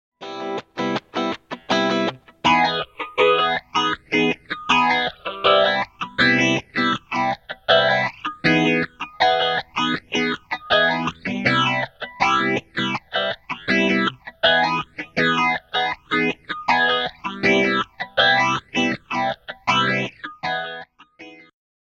Subtle swirl, bigger space. Switch sound effects free download
Switch on the MS-70CDR+'s phaser effect and let your chords breathe.